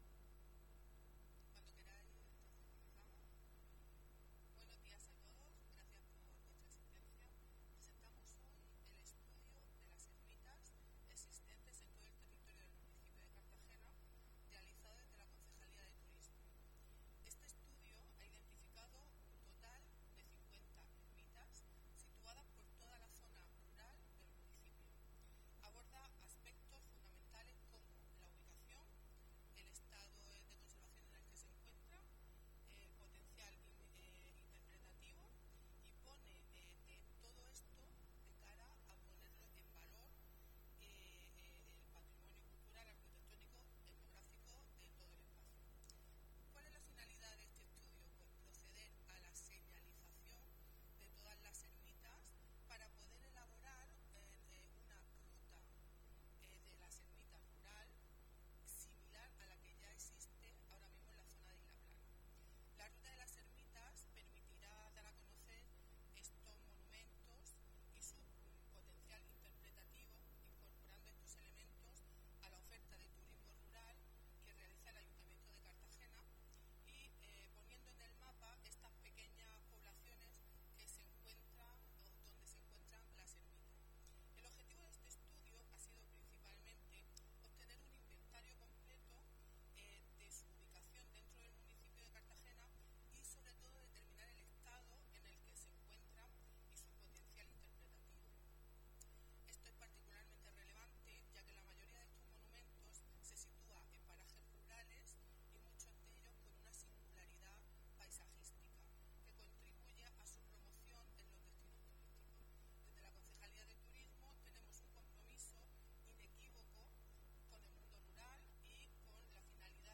El proyecto ha sido presentado por Beatriz Sánchez del Álamo, concejal delegada de Turismo, y Pablo Braquehais, concejal de Patrimonio y Vivienda.